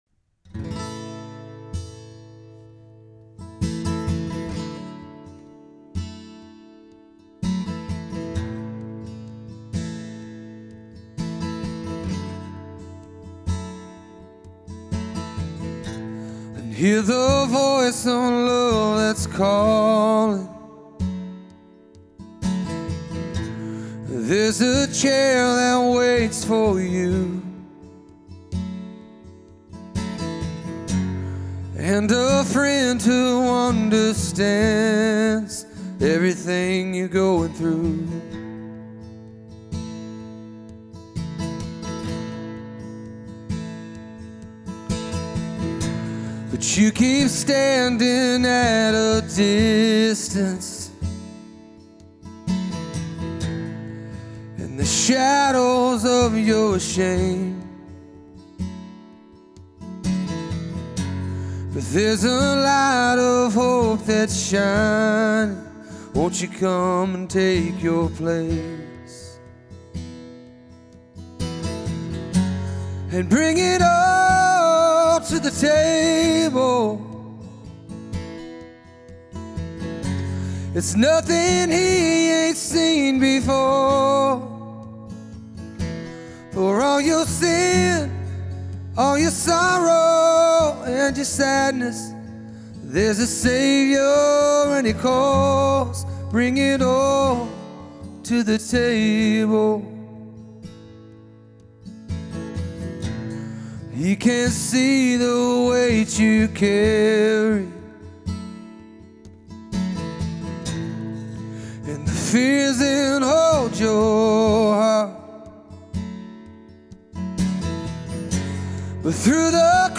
1. Sunday Worship – First Song: